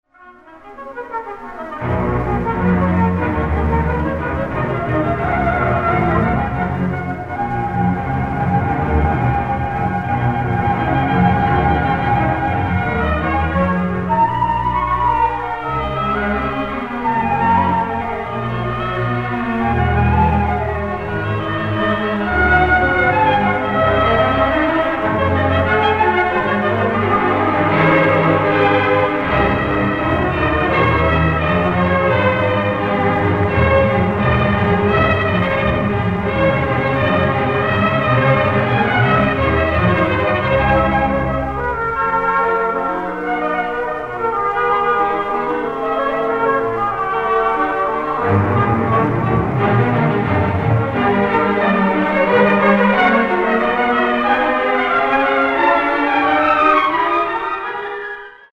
lush, exhuberant treatments